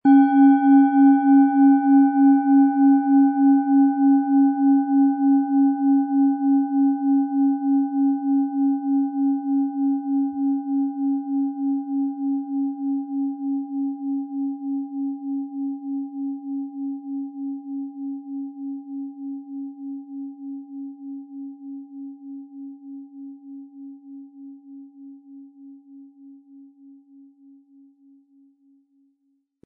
Planetenschale® Offen und Weit fühlen & Ausgeglichen fühlen mit OM-Ton, Ø 19,1 cm, 800-900 Gramm inkl. Klöppel
OM Ton
• Mittlerer Ton: Biorhythmus Geist
HerstellungIn Handarbeit getrieben
MaterialBronze